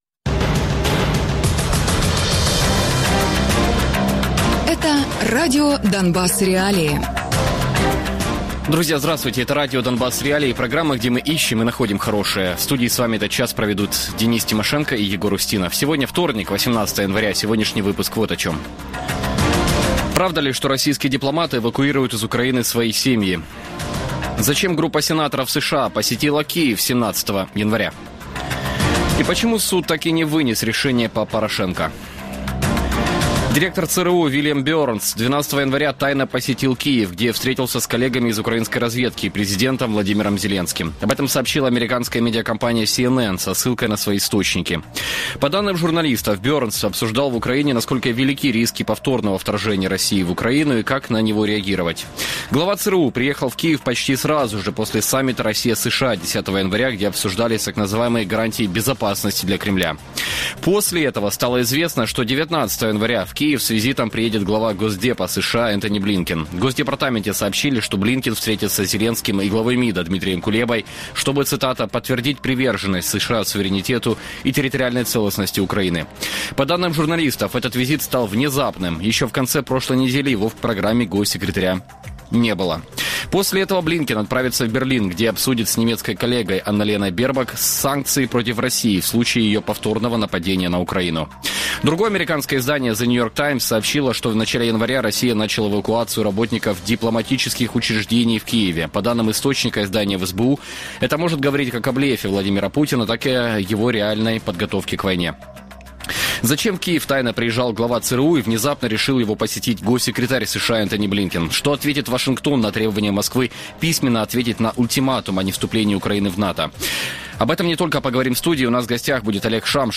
Гості: Олег Шамшур - колишній посол України в США (2005-2010 рр.), Віталій Портников - оглядач Радіо свобода